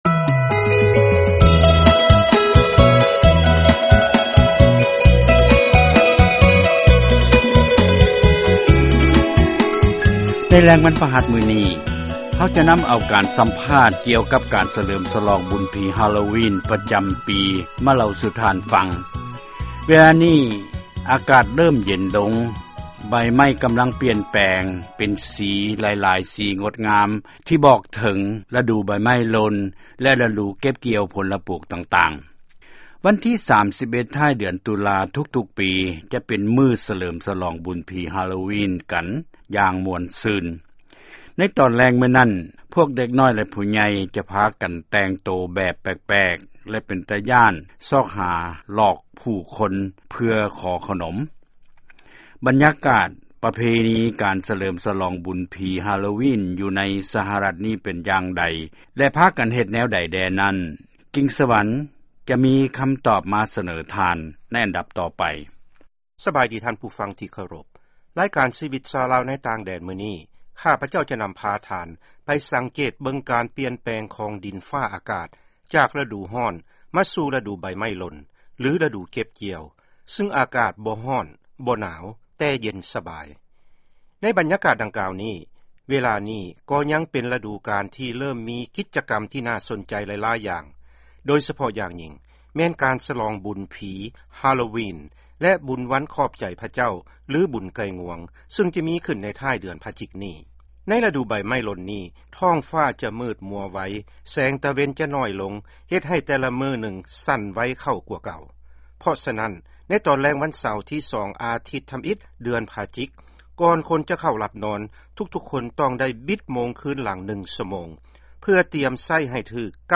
ຟັງການສໍາພາດ ການຫລີ້ນບຸນຜີ ຮາໂລວິນ